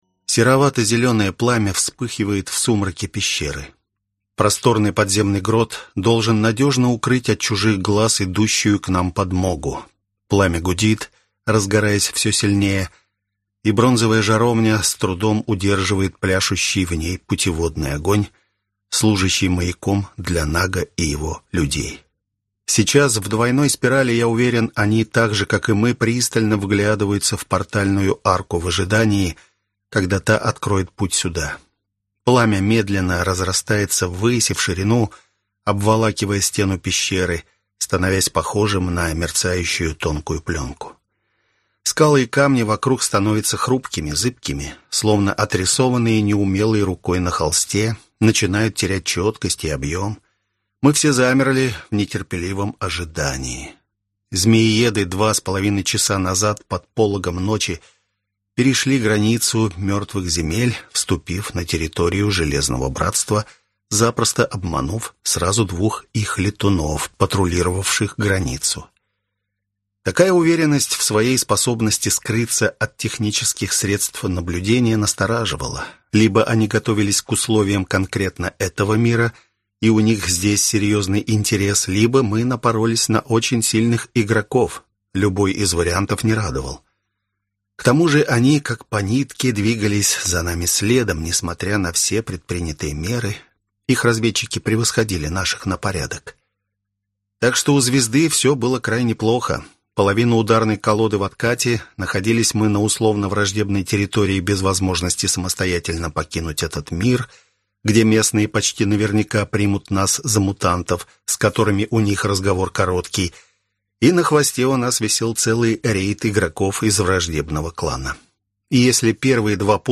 Аудиокнига На пути к могуществу | Библиотека аудиокниг